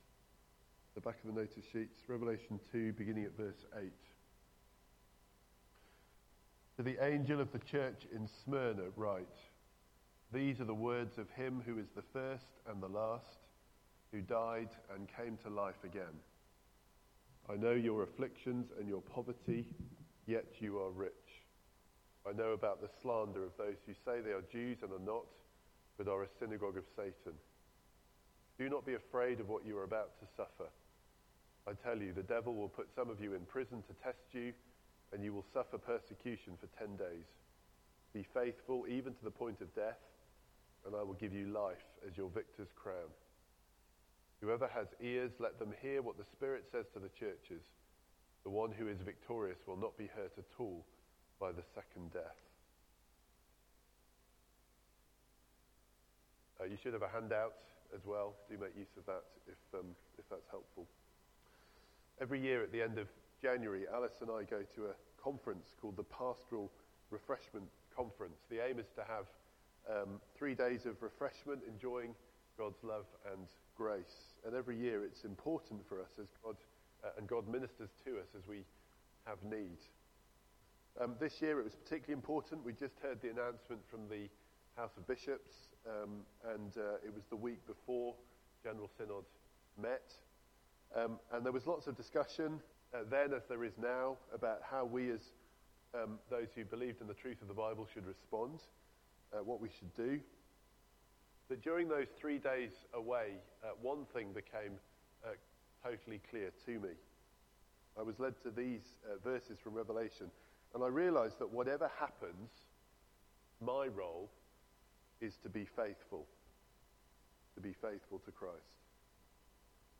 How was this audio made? Media Library The Sunday Sermons are generally recorded each week at St Mark's Community Church.